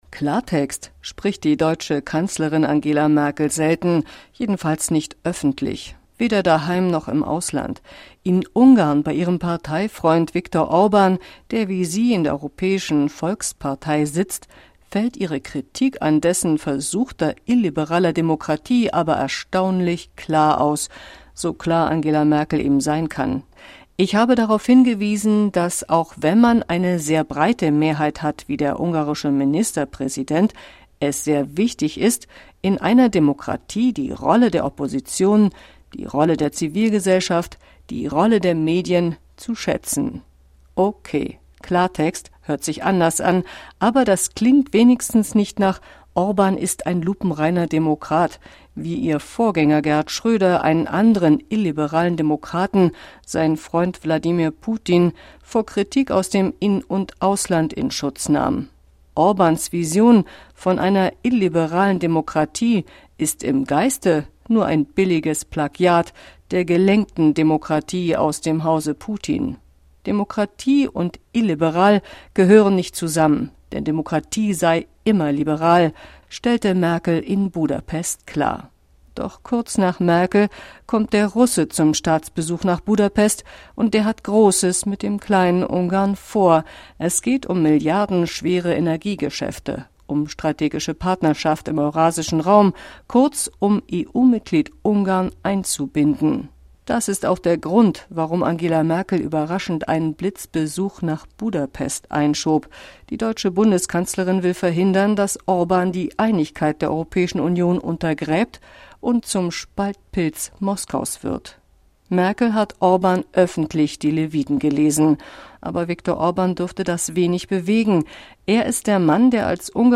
Kommentar
Merkels-Klartext-in-Orbanistan.Kommentar-XL-Fassung.mp3